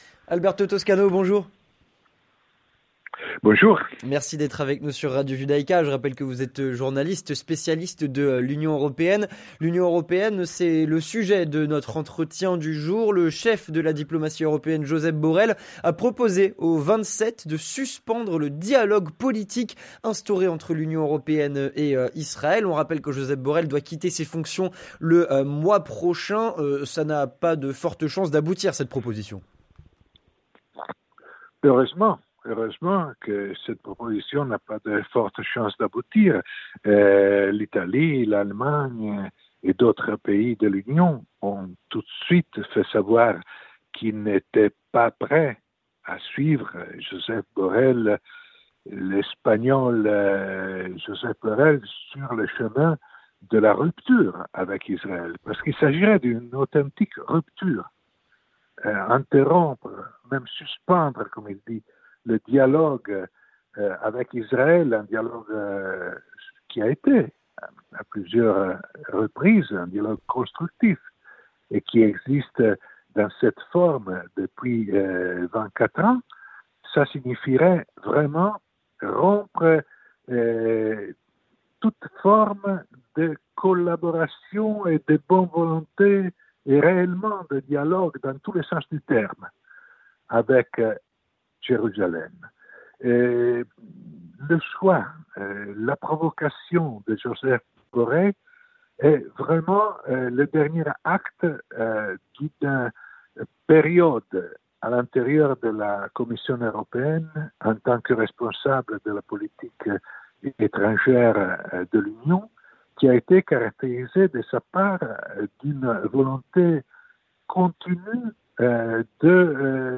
journaliste et spécialiste de l'Union Européenne